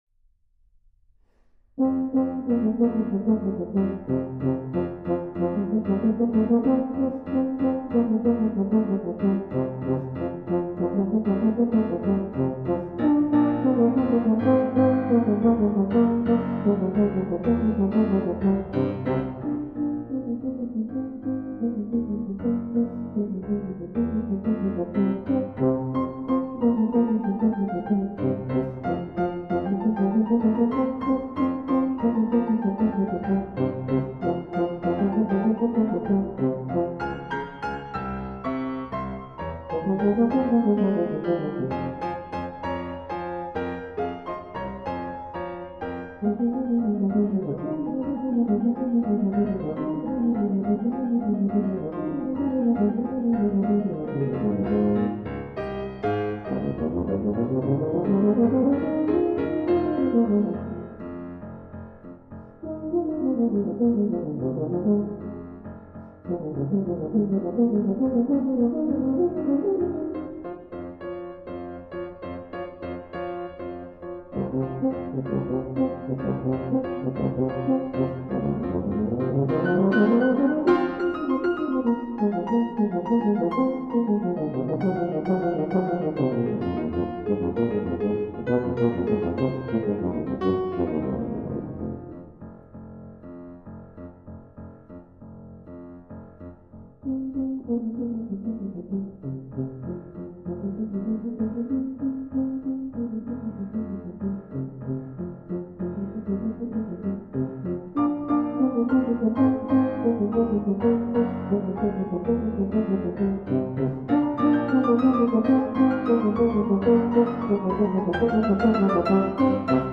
avec piano
Tuba / Tuba ut, Tuba mib (Solo)
Composition Originale
facile - medium
Accompagnement au piano